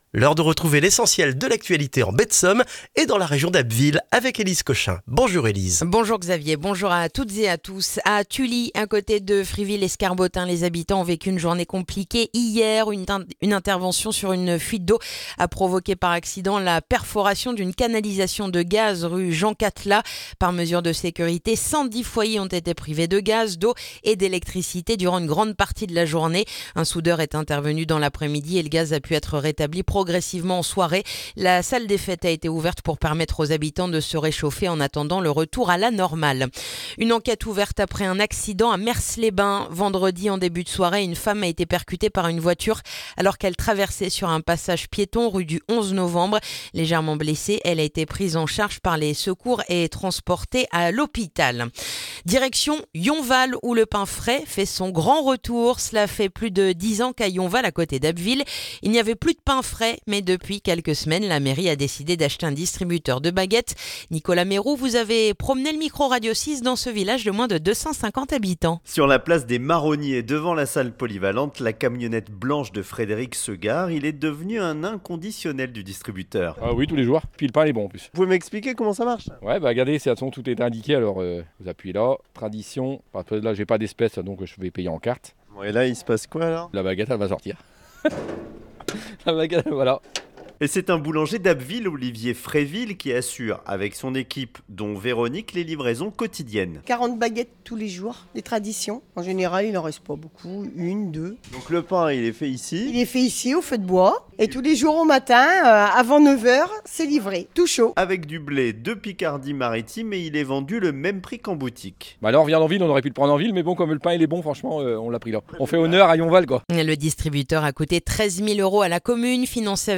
Le journal du lundi 12 janvier en Baie de Somme et dans la région d'Abbeville